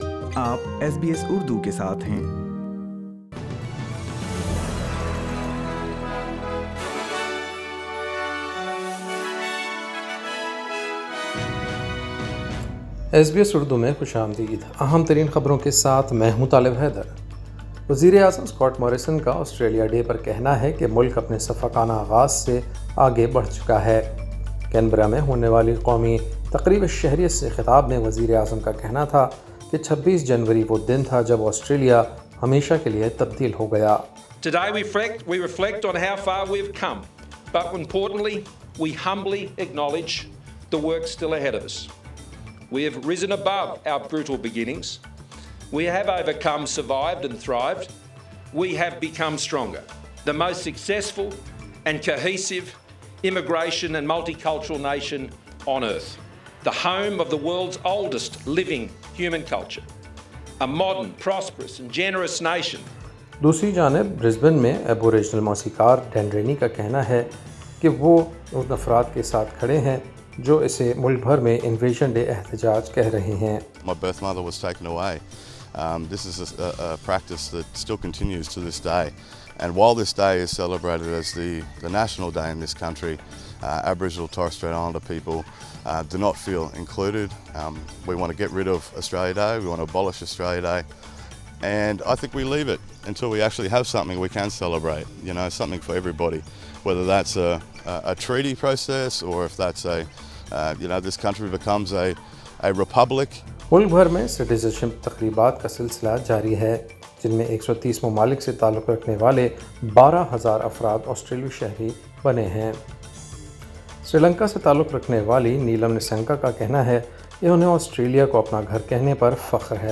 ایس بی ایس اردو خبریں 26 جنوری 2020